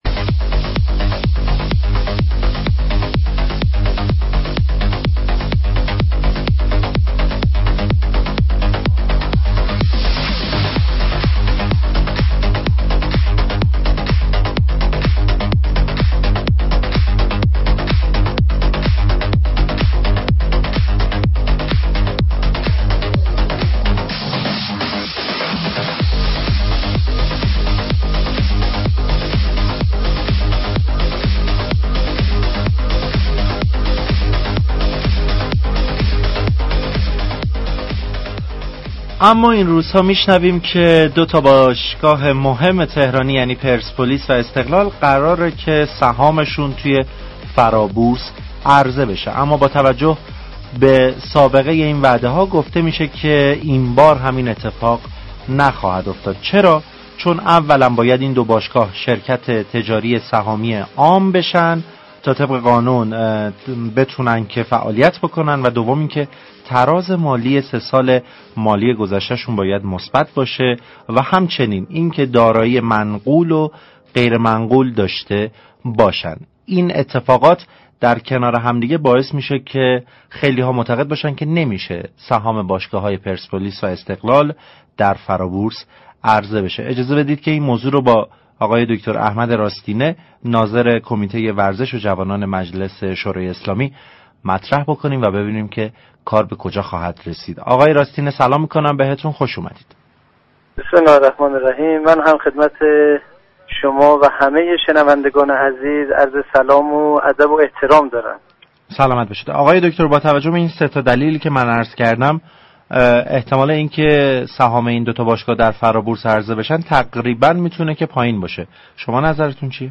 در این زمینه با بازار تهران رادیو تهران گفتگو كردند